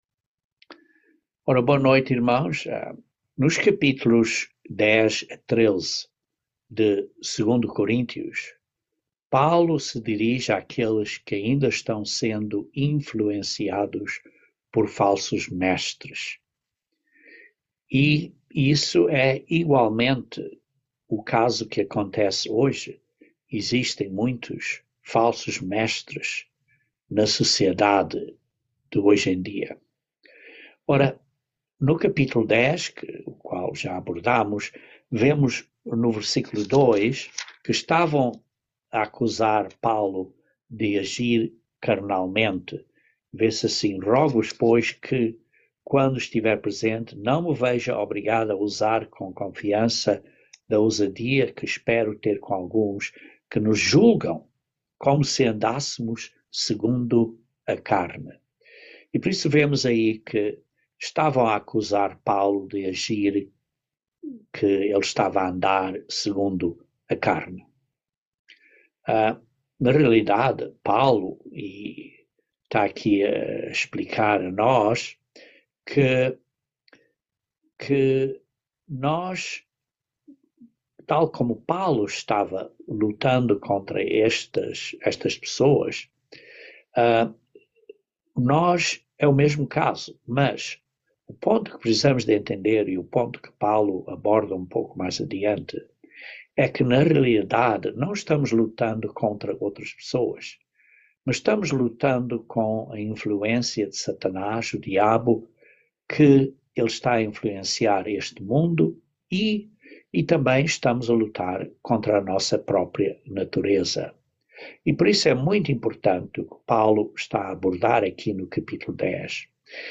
Estudo Bíblico
Given in Patos de Minas, MG